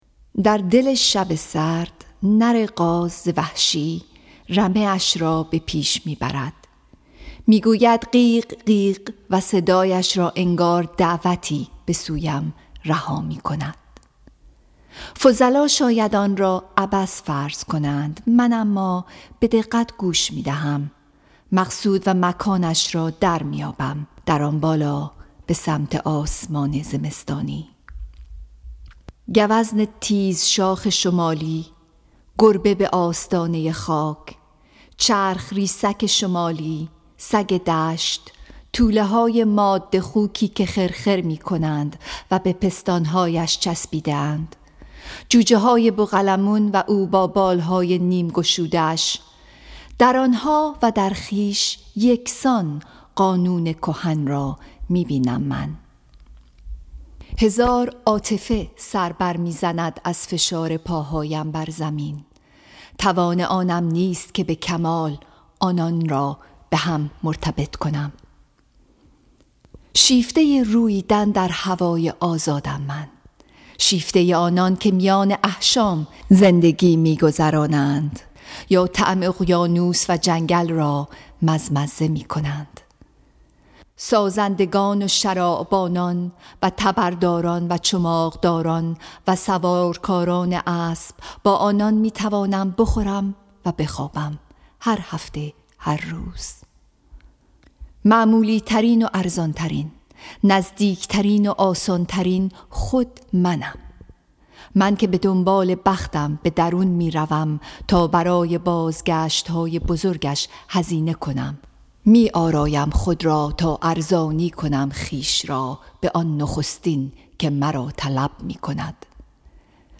Song of Myself, Section 14 —poem read